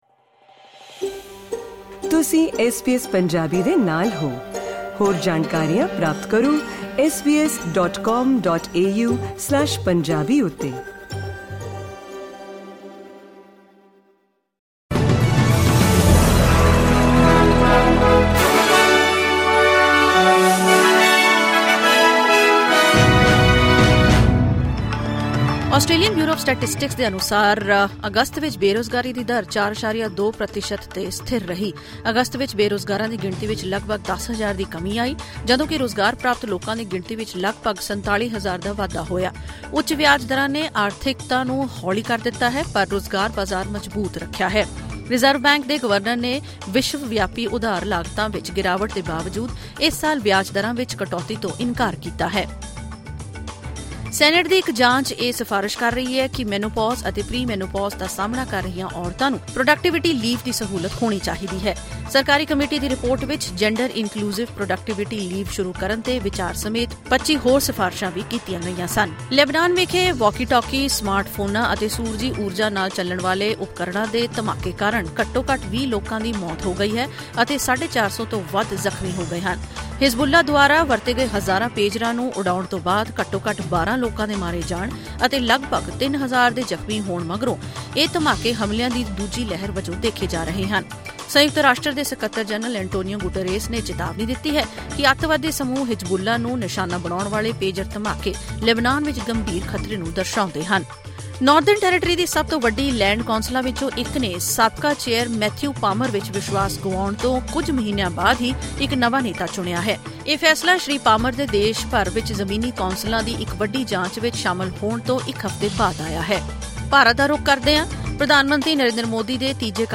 ਐਸ ਬੀ ਐਸ ਪੰਜਾਬੀ ਤੋਂ ਆਸਟ੍ਰੇਲੀਆ ਦੀਆਂ ਮੁੱਖ ਖ਼ਬਰਾਂ: 19 ਸਤੰਬਰ 2024